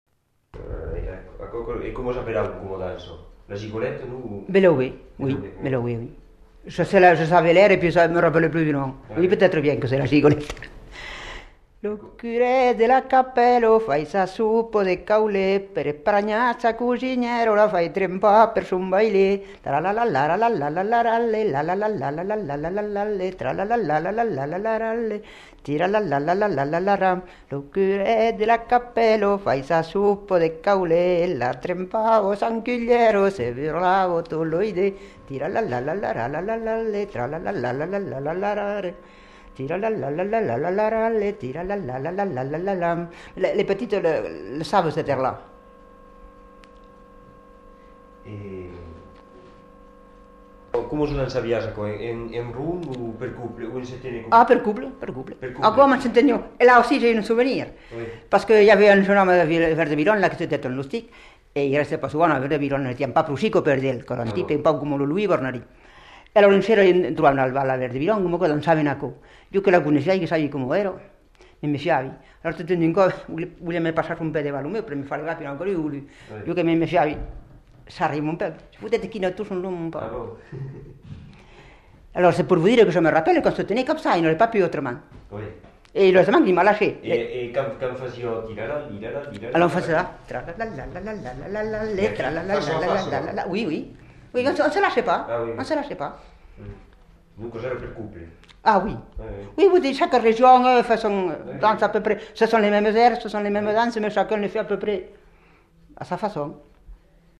Lieu : Castillonnès
Genre : chant
Effectif : 1
Type de voix : voix de femme
Production du son : chanté
Danse : gigue